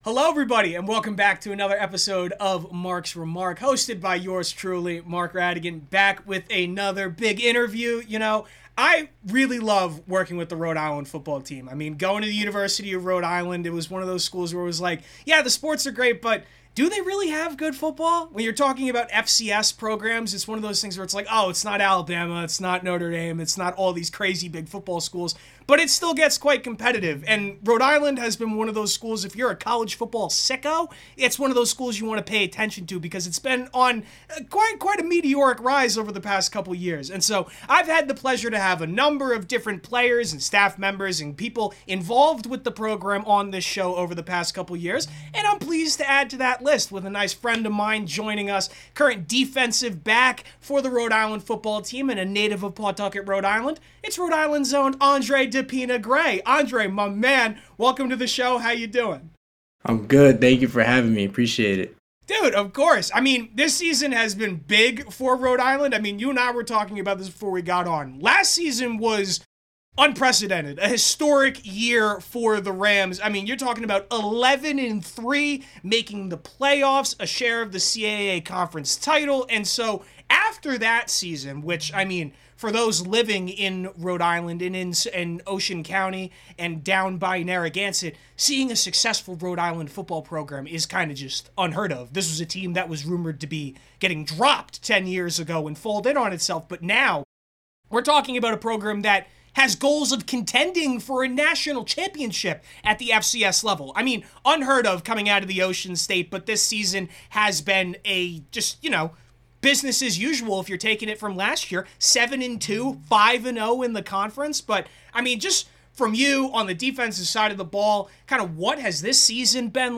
conversations with your favorite athletes, coaches, and staff members